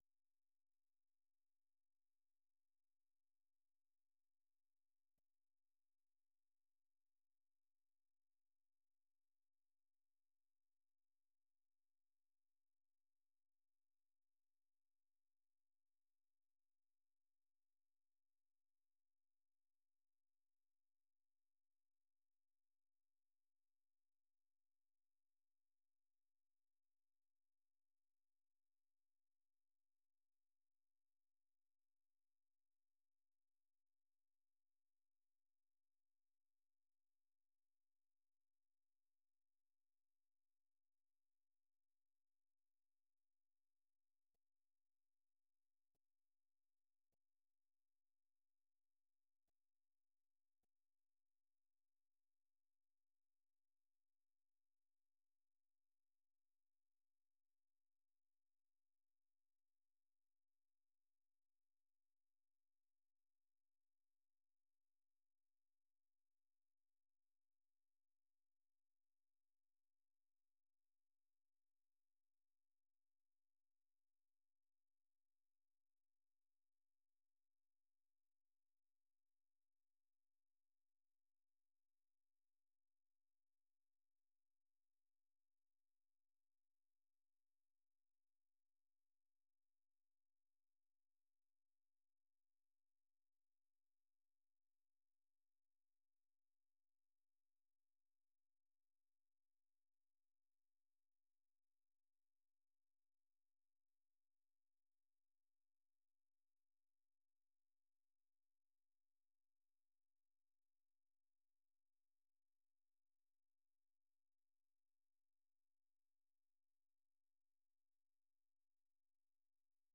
VOA 한국어 방송의 일요일 새벽 방송입니다. 한반도 시간 오전 2:00 부터 3:00 까지 방송됩니다.